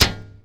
shield-hit-12.mp3